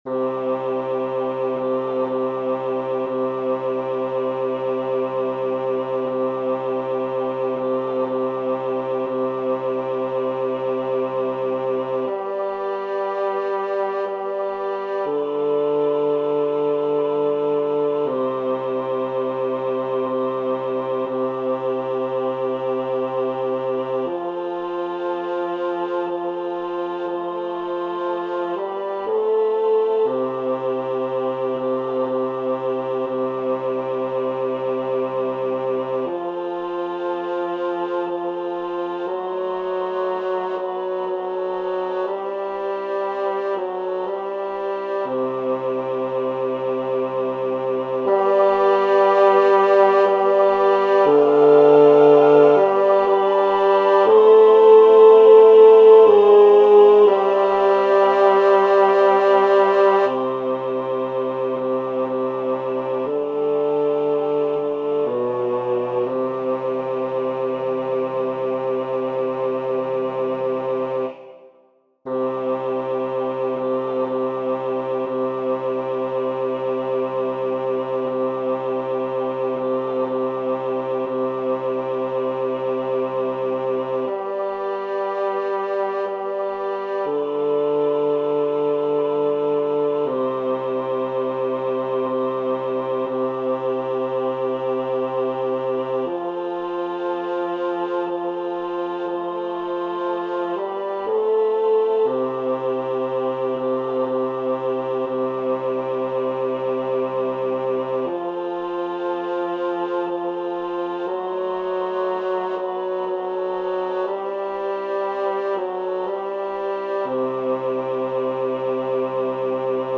El tempo está marcado como negra=60. Es una canción lenta, en la que hay que estirar al máximo las vocales y respirar de forma muy pausada y tranquila.
Para aprender la melodía os dejo estos MIDIS, con la voz principal destacada por encima del resto.
noche-de-paz-bajo.mp3